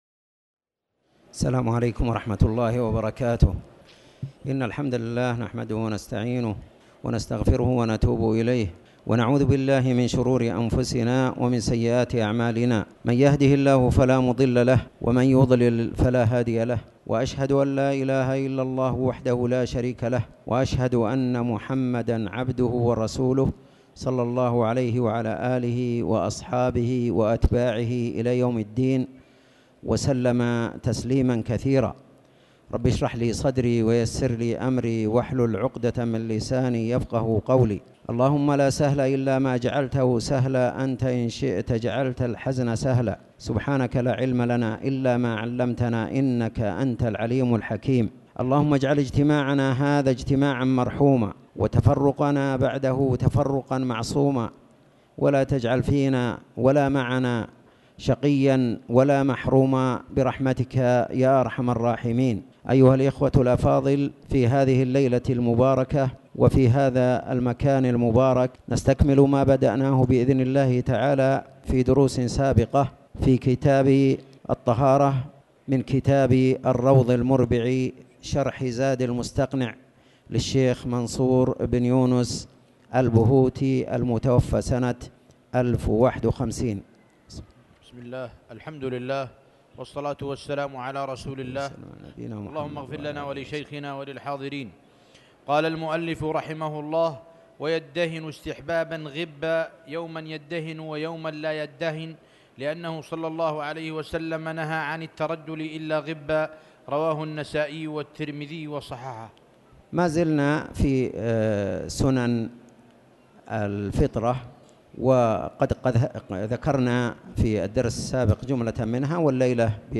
تاريخ النشر ١٧ جمادى الآخرة ١٤٣٩ هـ المكان: المسجد الحرام الشيخ